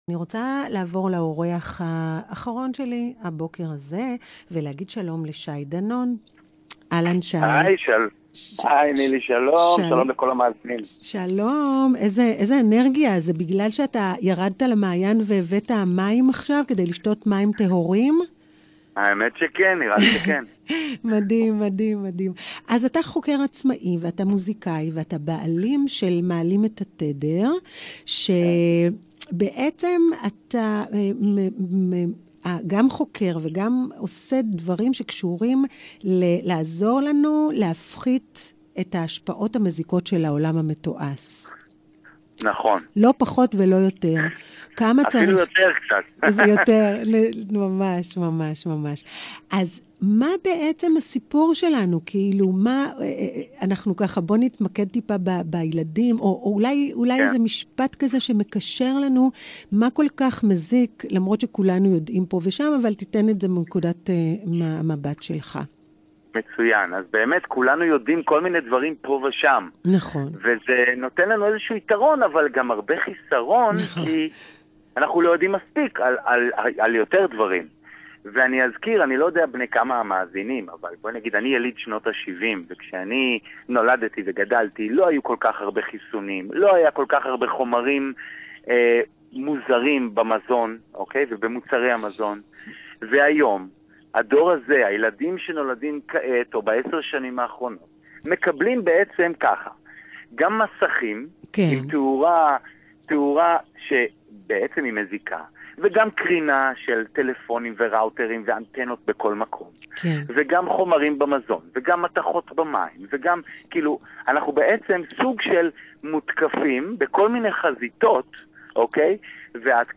ראיון רדיו : להגן על הילדים בעולם דיגיטלי מתועש